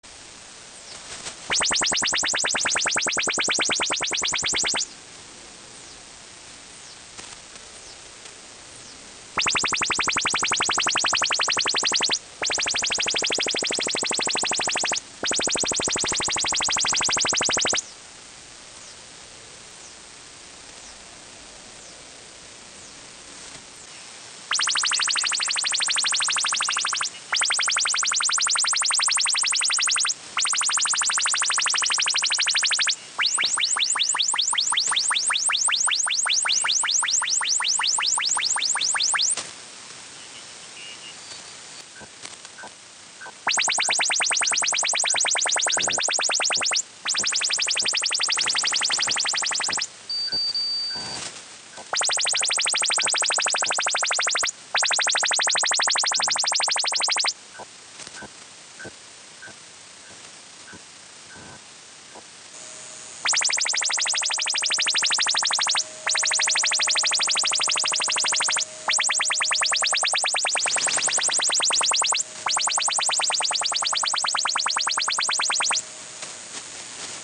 Вы можете прослушать и скачать записи работы станции: гул генераторов, щелчки антенн и другие технические шумы.
Звук передвижного современного радиолокатора за горизонтом (8кГц)